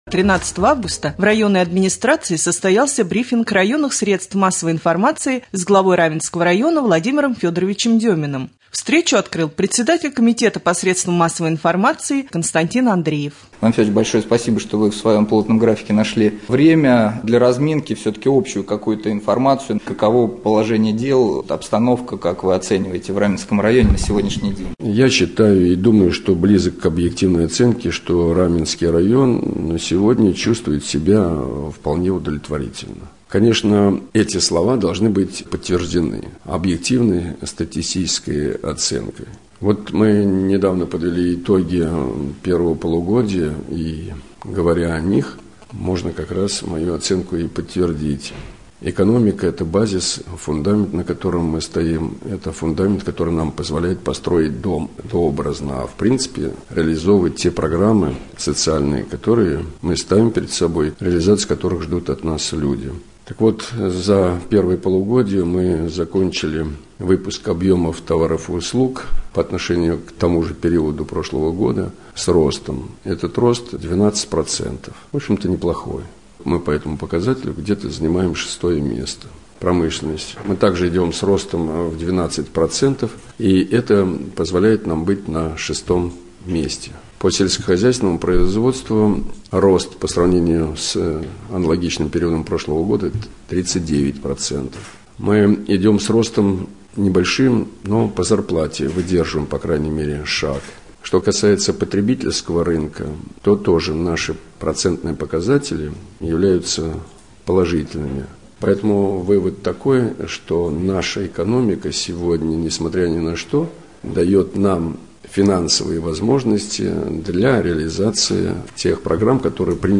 Брифинг районных СМИ с главой района В.Ф.Деминым